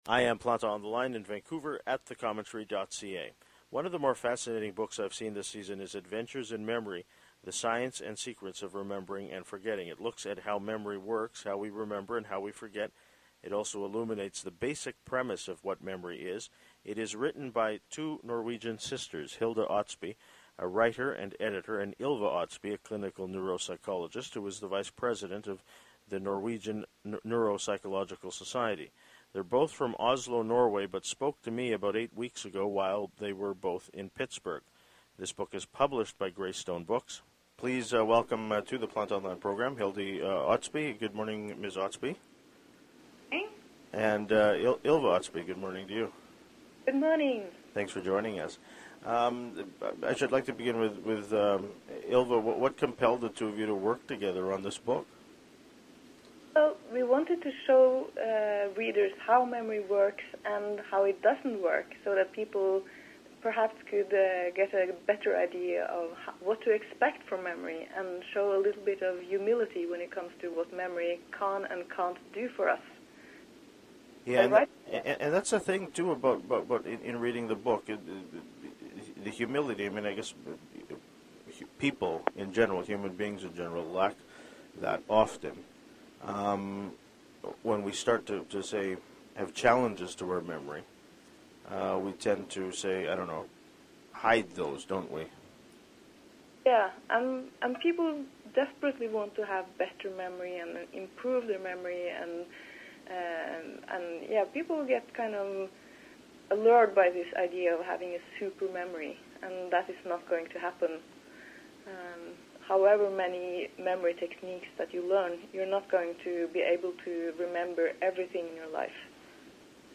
They’re both from Oslo, Norway, but spoke to me about eight weeks ago while they were both in Pittsburgh.